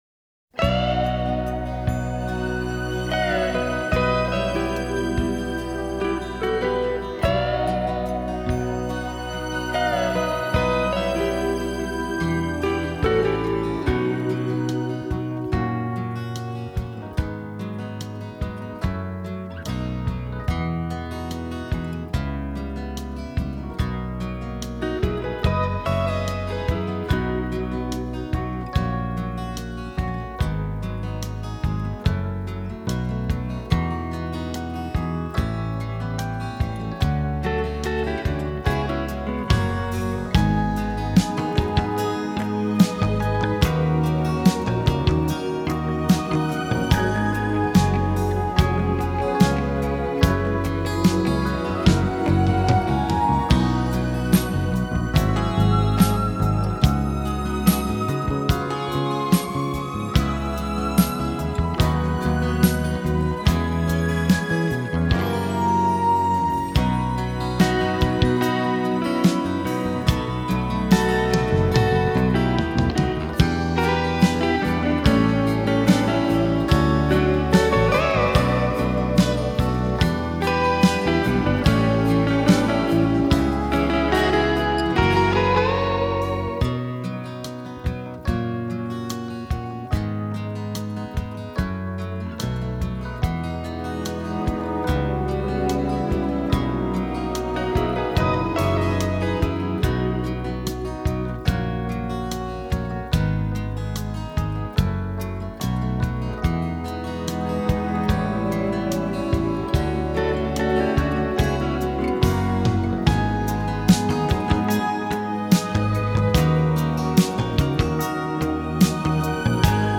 Karaoke